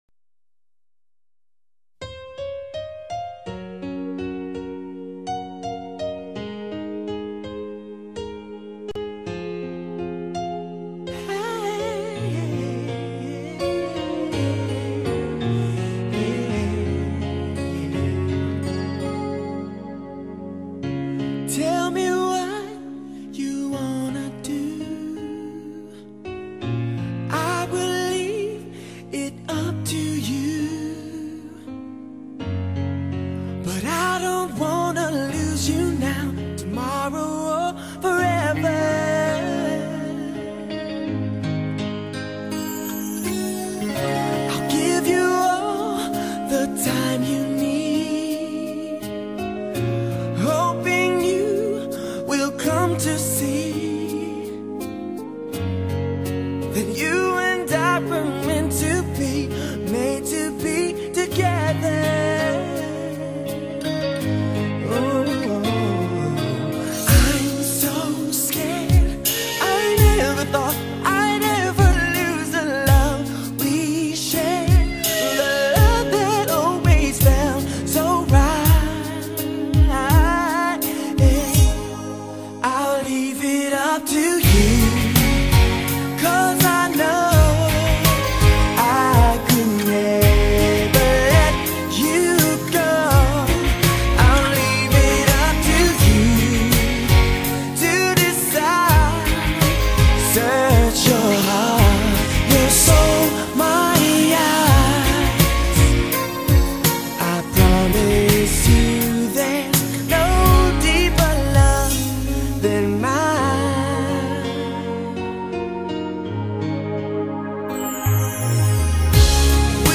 留住真爱一生的最初感动，唤醒歌迷不灭记忆的旷世情歌大赏，